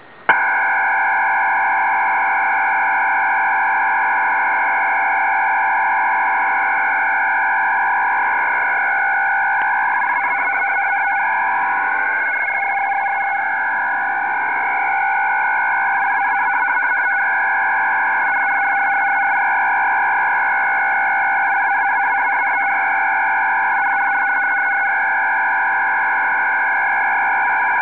MCVFT-systems (Multichannel VFT)
3 x FEC 192 Bd